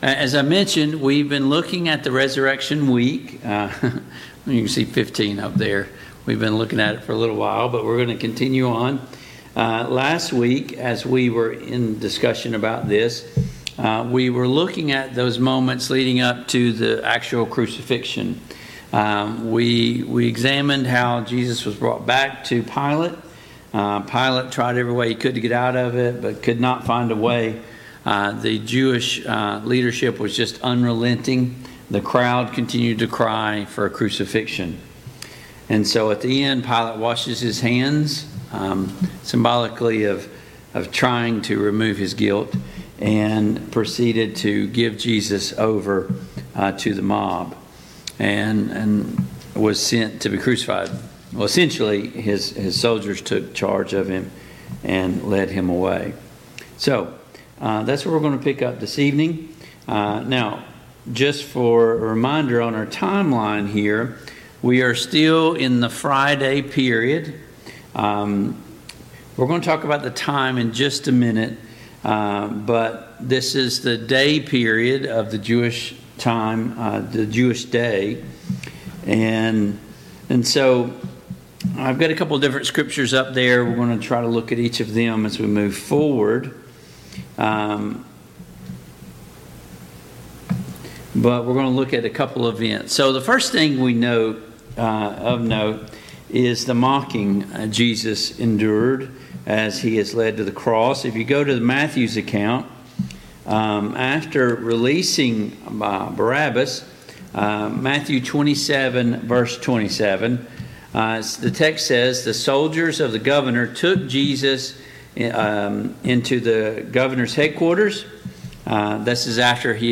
Service Type: Mid-Week Bible Study